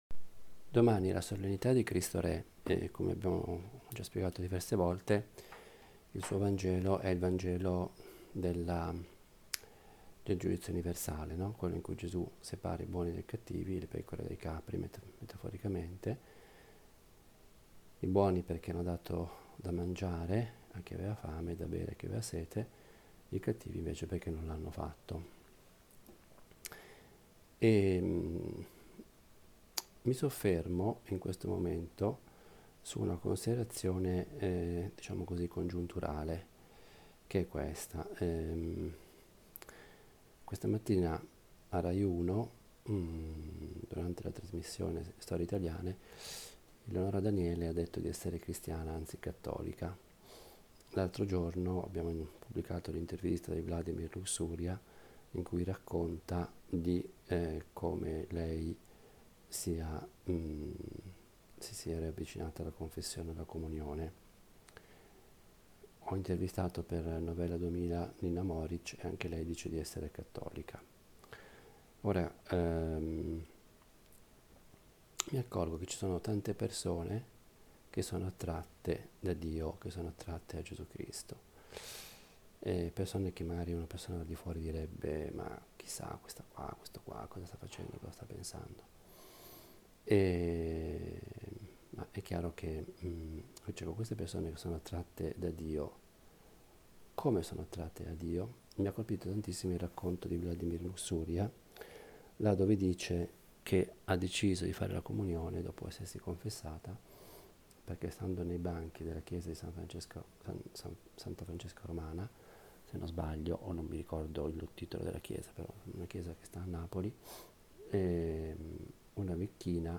Pausa caffè a Nazareth è una riflessione breve, di otto minuti, sul vangelo di domenica.
Vorrei avesse il carattere piano, proprio di una conversazione familiare. Io la intendo come il mio dialogo personale – fatto ad alta voce – con Dio e con la Madonna.